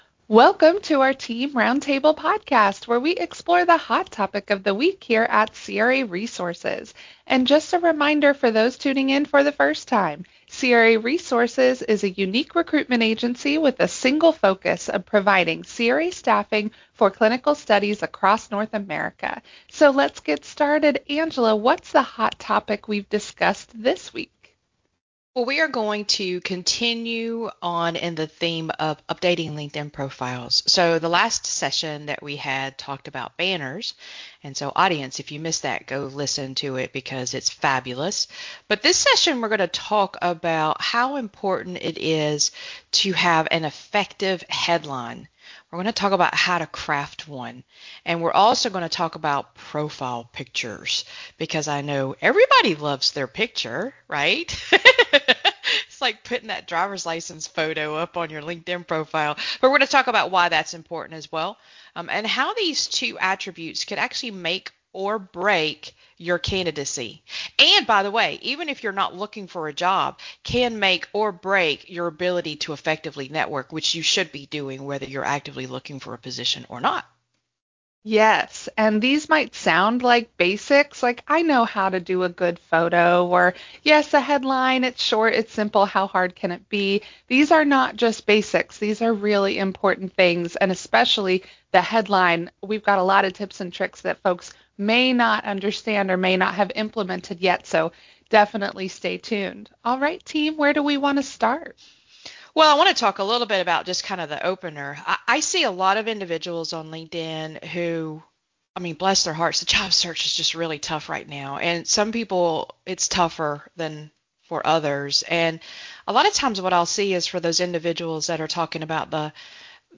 In our latest roundtable, our team dives into two of the most underestimated areas of a LinkedIn profile: your headline and your photo.